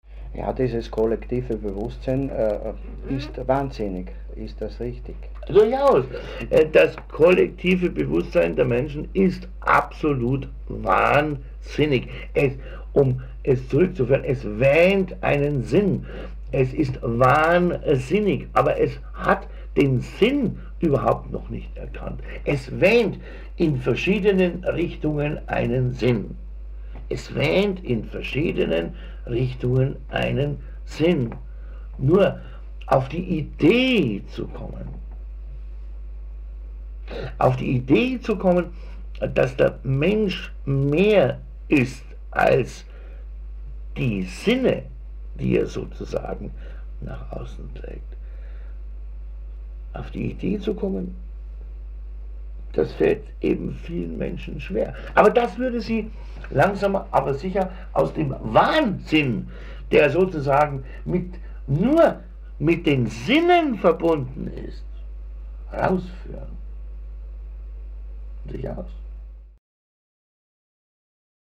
Alle Aufnahmen entstanden in Vorarlberg/Österreich.
Diese Serie von Readings entstanden in den Jahren 1996 bis 1999 und wurden auf Audio-Kassetten mitgeschnitten.
Leider sind viele dieser CDs im laufe der Jahre von der Tonqualität her sehr in Mitleidenschaft gezogen, d.h. unbrauchbar, worden.